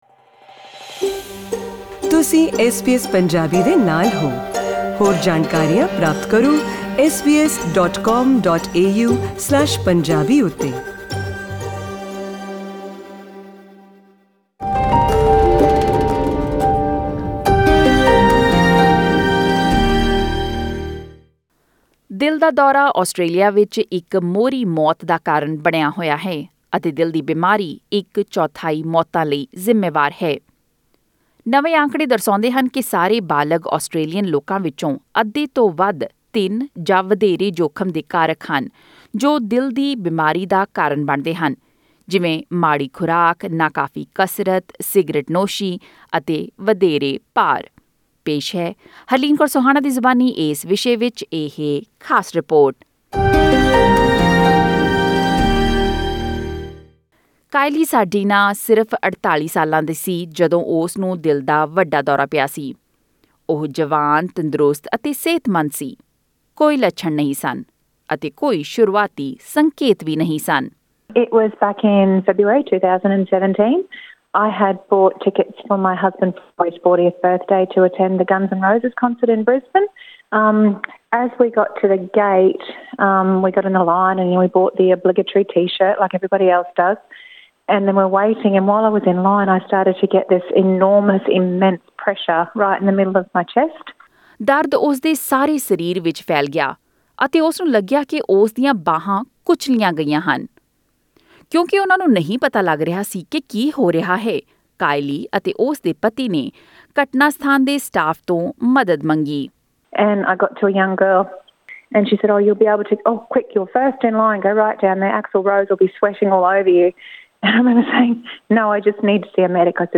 " Click on the player at the top of the page to listen to the news bulletin in Punjabi.